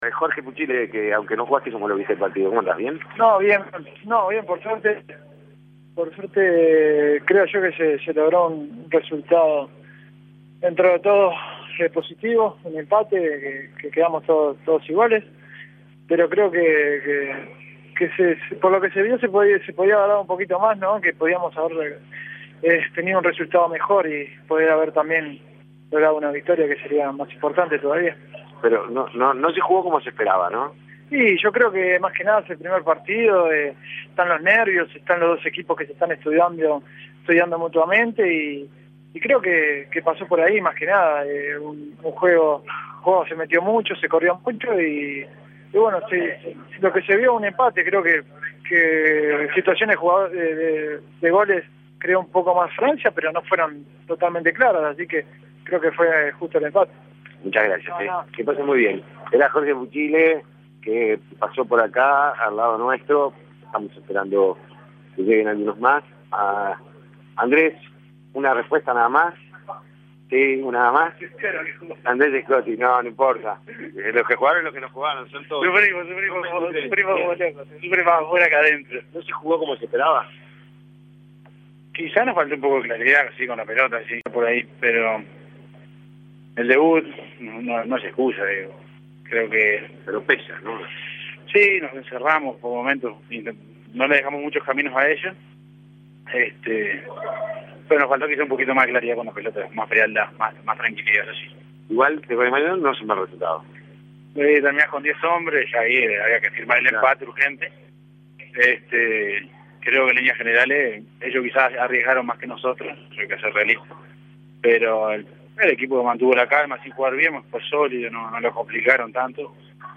Escuche los testimonios de los jugadores de Uruguay y la conferencia de prensa de Oscar Washington Tabárez luego del empate ante Francia.